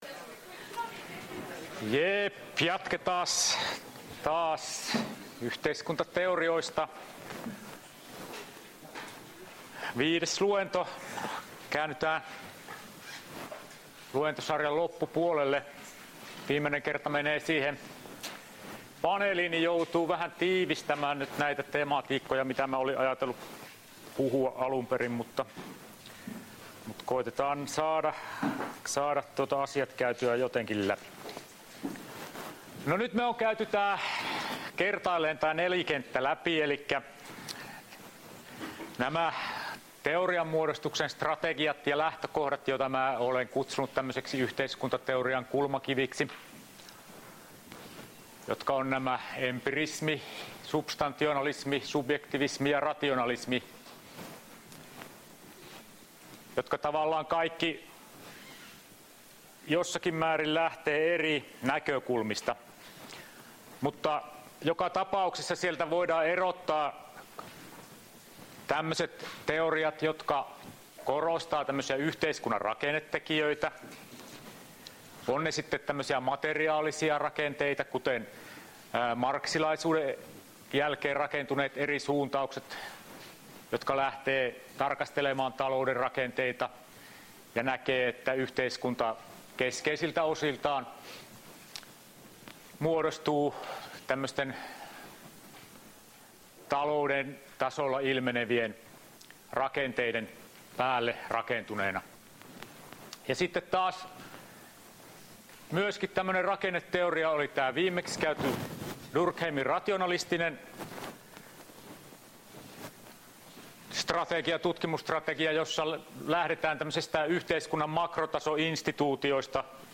Luento 12.11.2018